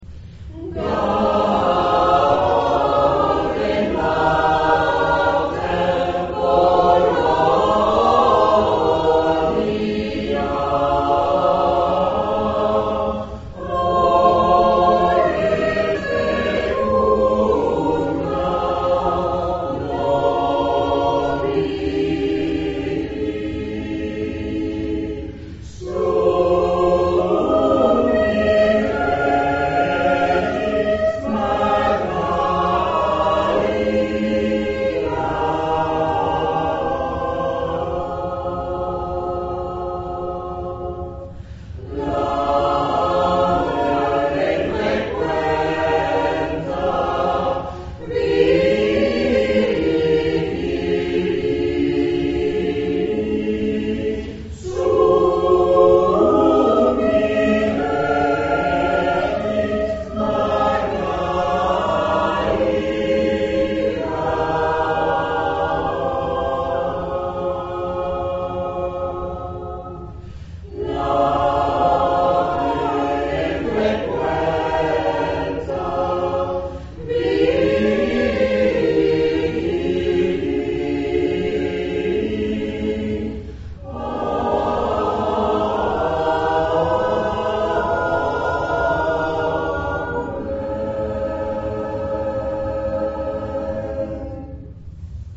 Ce 29 novembre, une place particulière a bien sûr été consacrée aux chants de Noël dans de nombreuses langues.
Extraits du concert
Gaudete       noël du XVIe siècle
• Gaude Mater Polonia     chant trad. XVIIIe siècle